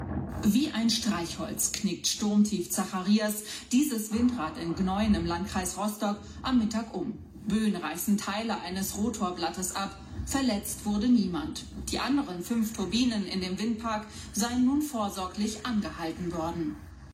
Windräder, wenn dann endlich mal Wind weht